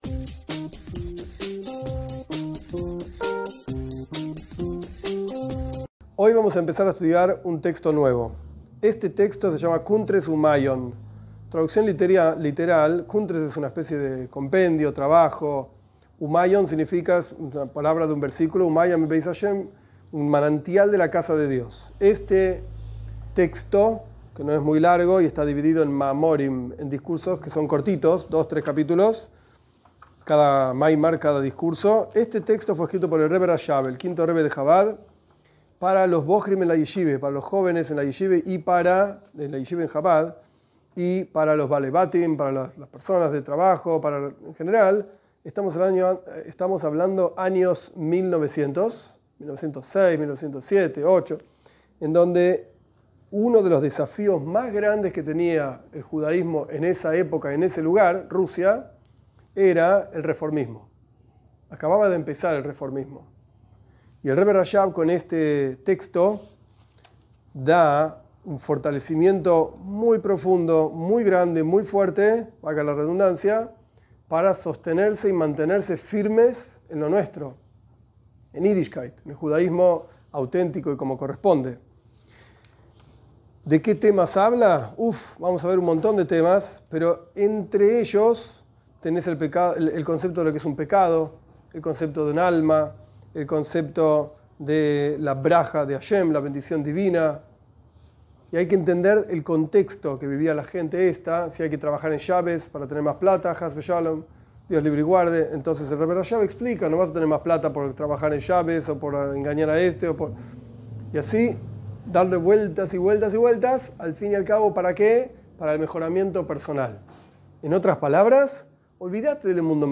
Esta es la primera clase de la serie "Superando impulsos". Se trata de la lectura y explicación del Kuntres uMaaian, del Rebe Rashab, sobre el refinamiento personal.